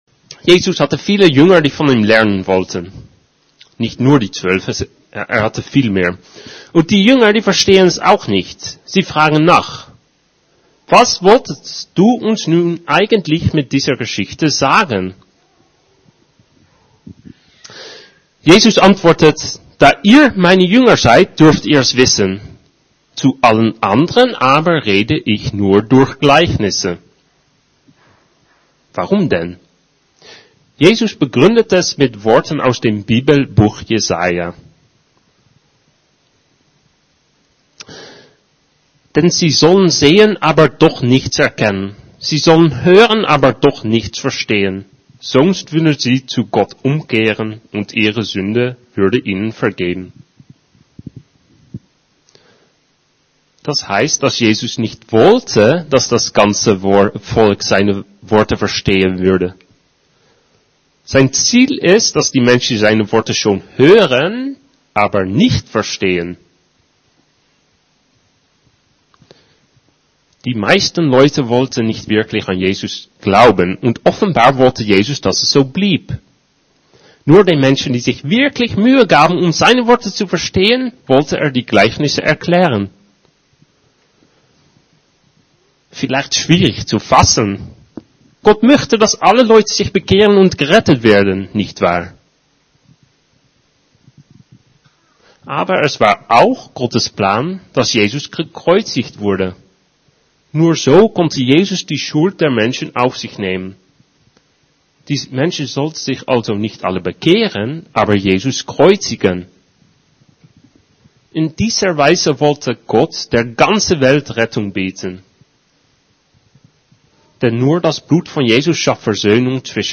Predigten FeG Schwerin
Hier sind die Predigten der wöchentlichen Gottesdienste der FeG Schwerin.